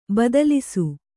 ♪ badalisu